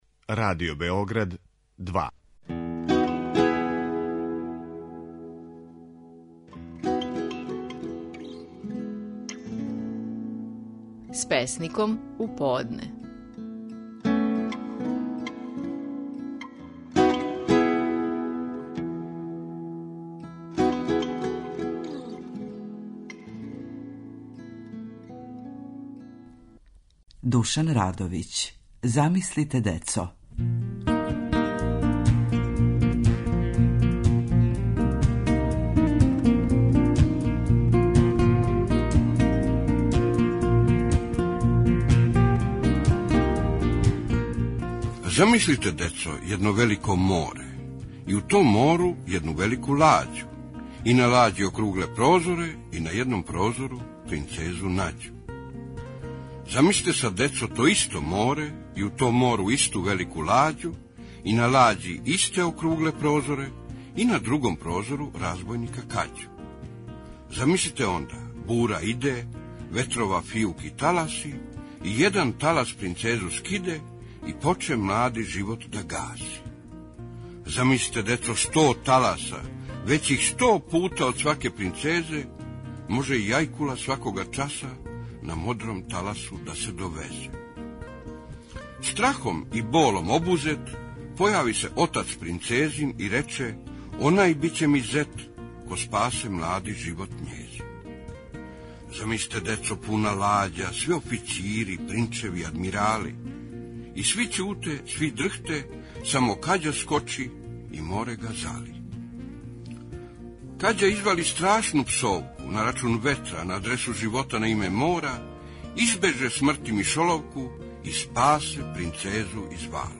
Наши најпознатији песници говоре своје стихове
Душко Радовић говори своју песму „Замислите, децо".